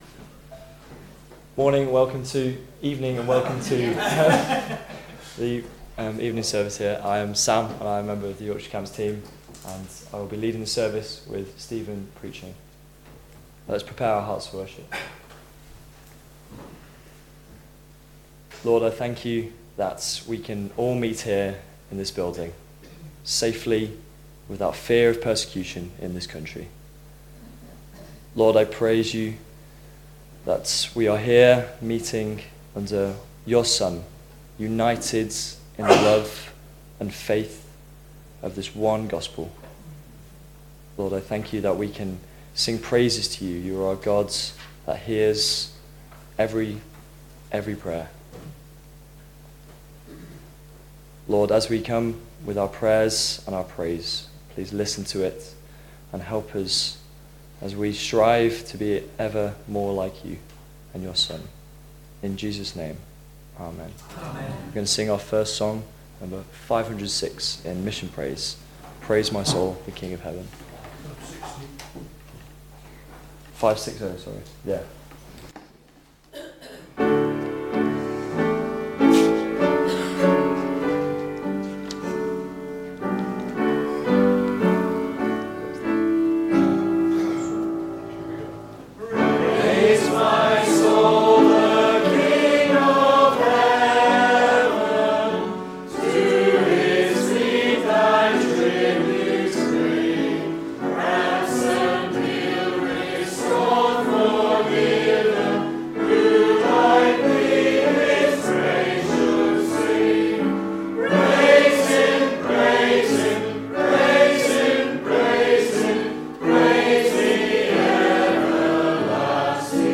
Below is audio of the full service.